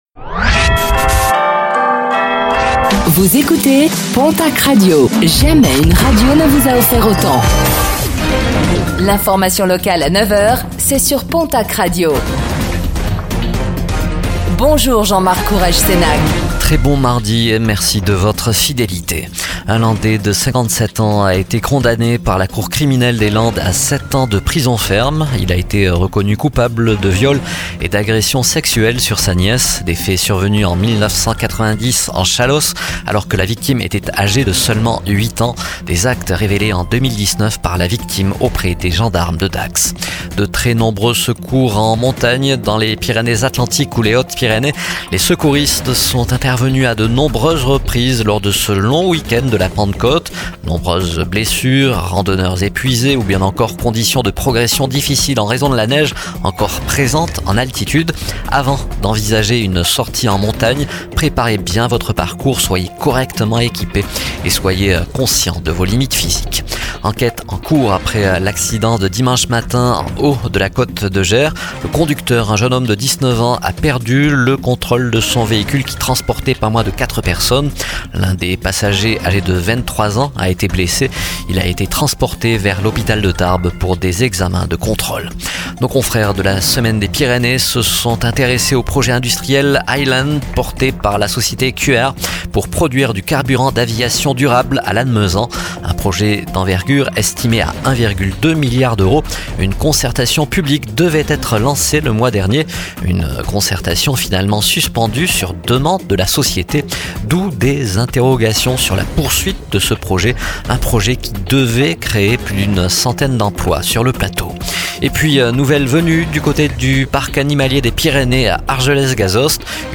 Réécoutez le flash d'information locale de ce mardi 10 juin 2025